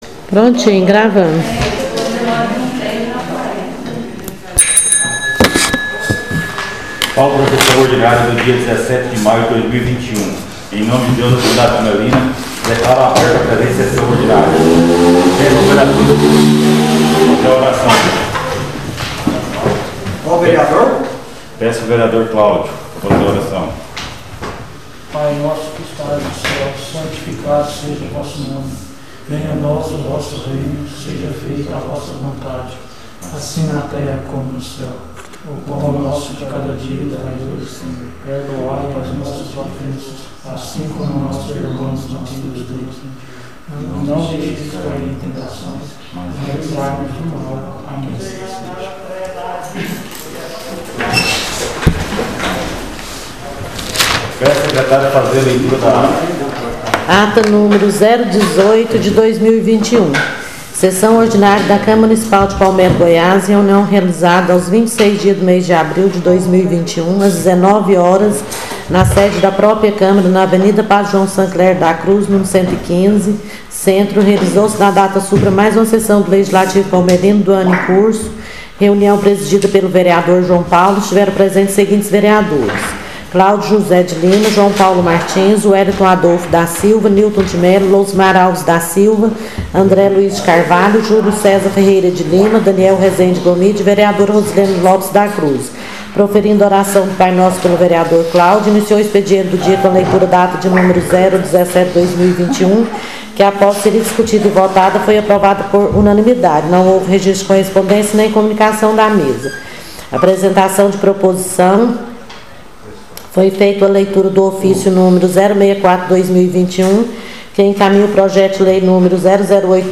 SESSÃO ORDINÁRIA DIA 24/05/2021